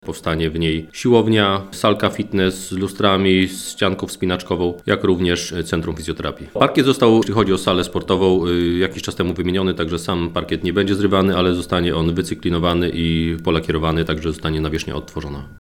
Obiekt będzie znacznie nowocześniejszy mówi burmistrz Baranowa Sandomierskiego Marek Mazur.